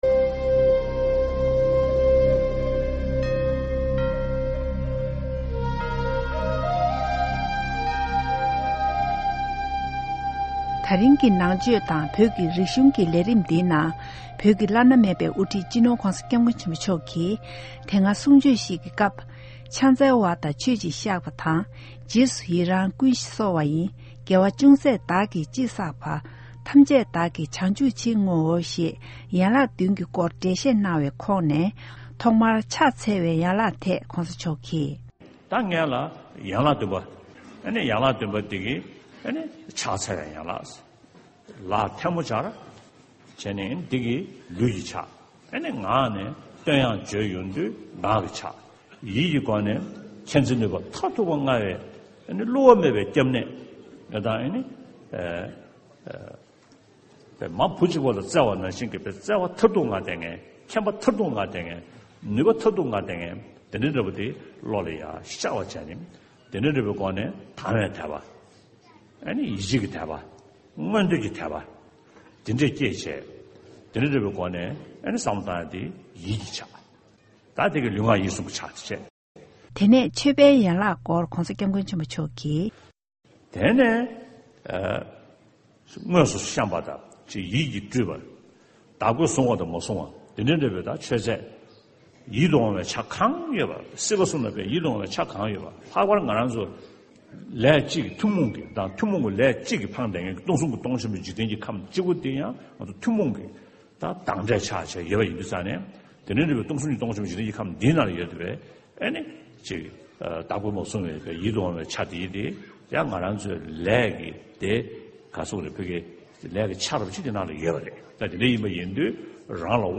བོད་ཀྱི་བླ་ན་མེད་པའི་དབུ་ཁྲིད་སྤྱི་ནོར་༧གོང་ས་༧སྐྱབས་མགོན་ཆེན་པོ་མཆོག་གིས་ཡན་ལག་བདུན་གྱི་སྐོར་འགྲེལ་བཤད་གནང་བ་ཞིག་གསན་གྱི་རེད།